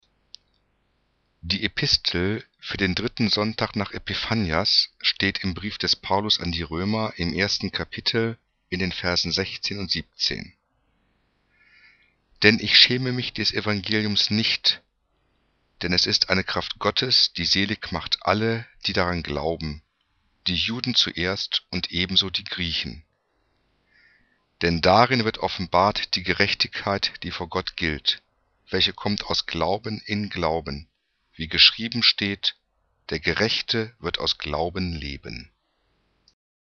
EpistelDritternachEpiphanias.mp3